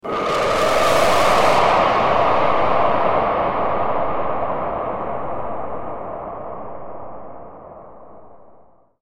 Ghost-scream.mp3